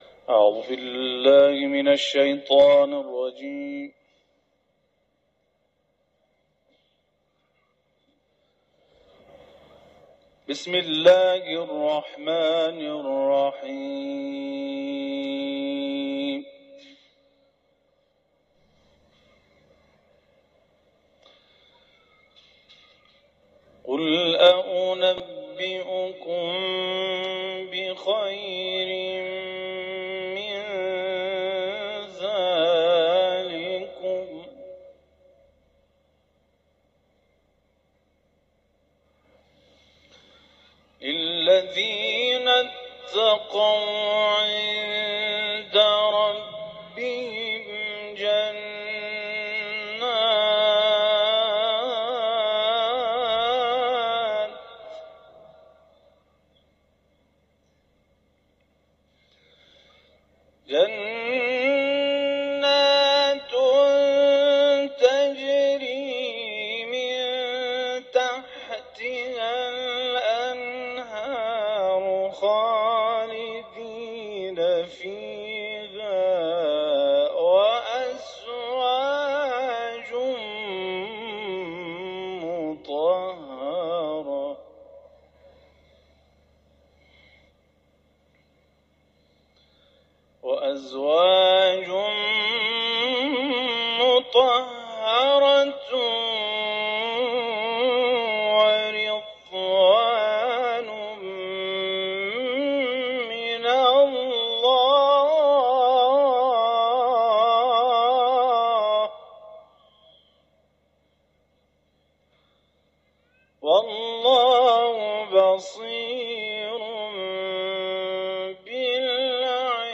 صوت این تلاوت در قسمت زیر قابل پخش است.
تلاوت